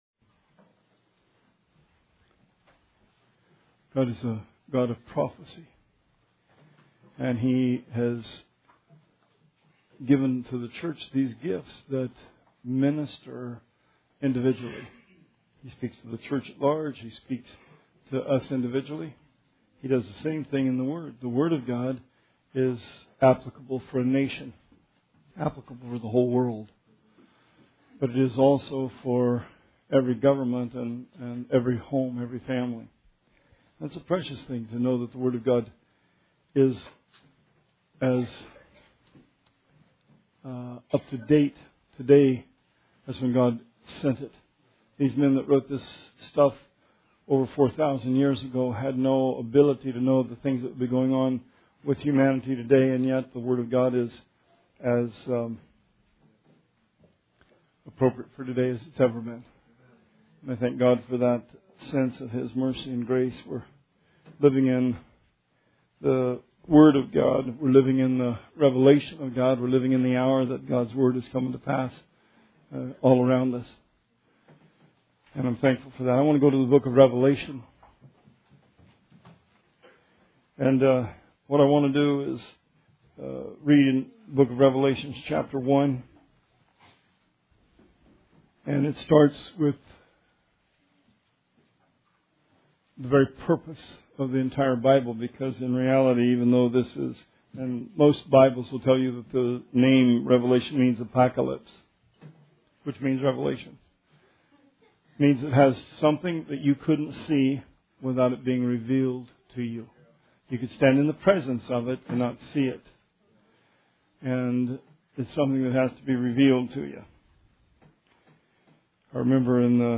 Sermon 1/29/17 – RR Archives